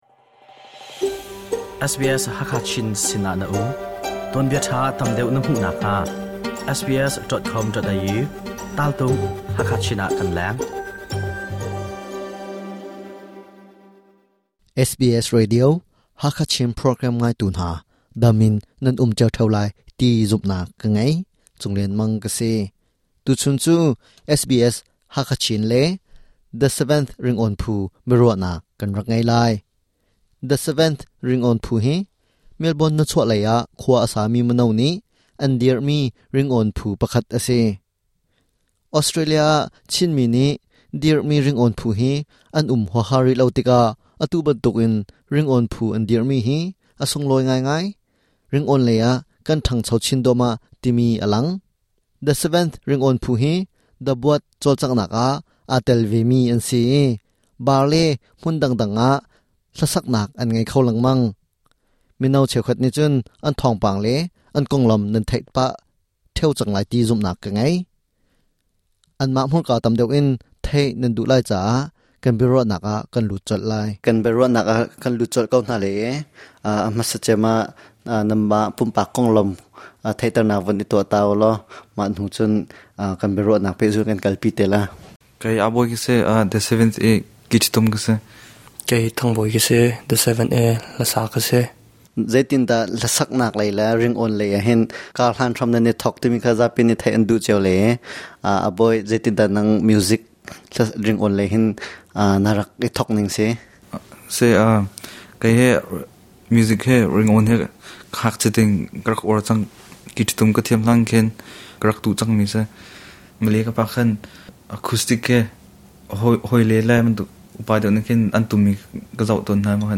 SBS Hakha Chin le 'The Seventh' Ringawn Phu Biaruahnak